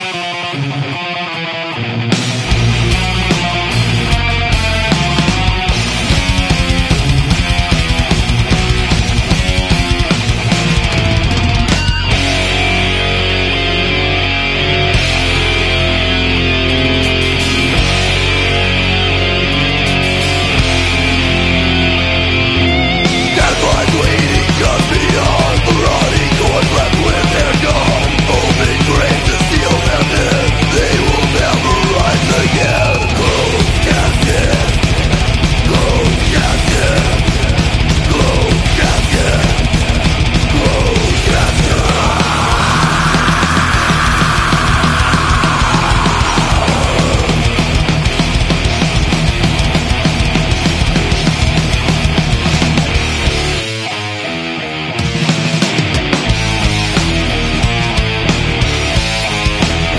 guitarra
batería
bajo
Género:Metal